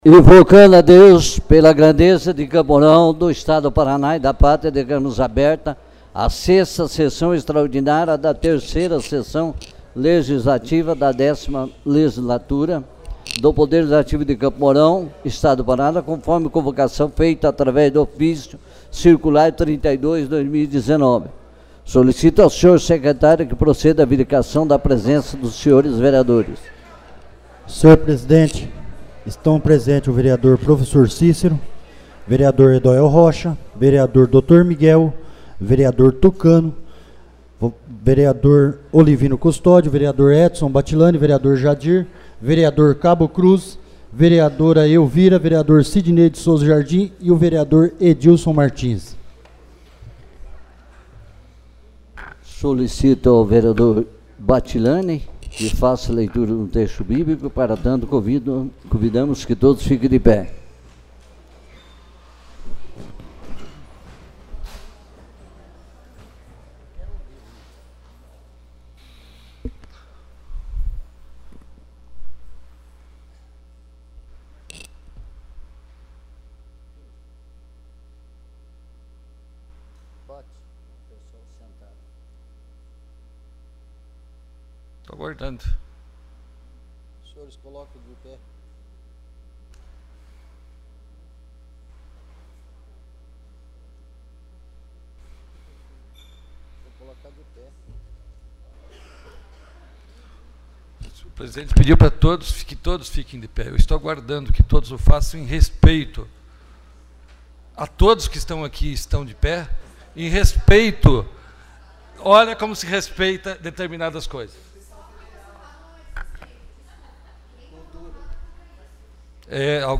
6ª Sessão Extraordinária